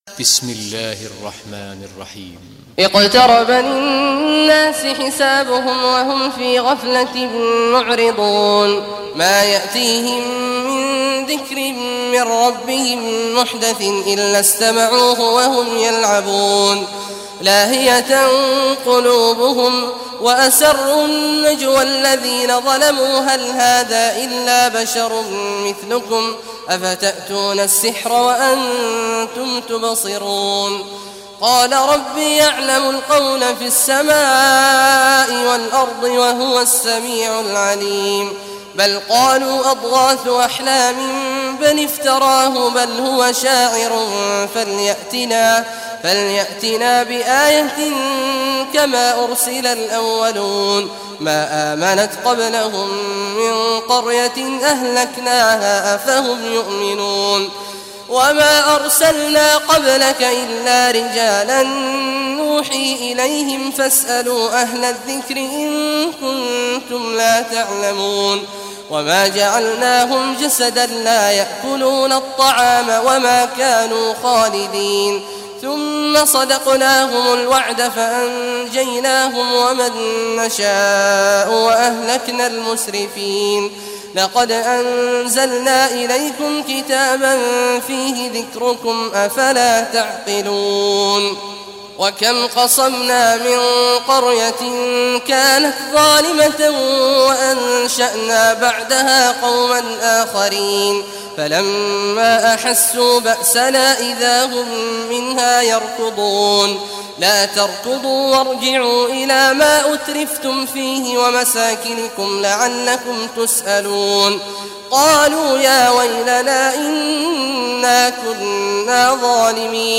Surah Anbya Recitation by Sheikh Abdullah Juhany
Surah Al-Anbya, listen or play online mp3 tilawat / recitation in Arabic in the beautiful voice of Sheikh Abdullah Awad al Juhany.